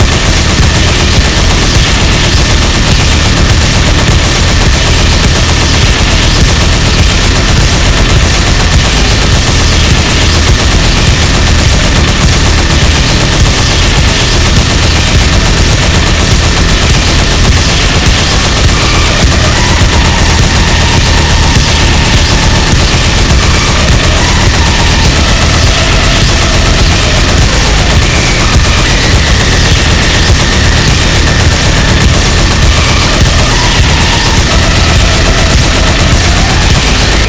gatling_shoot_crit.wav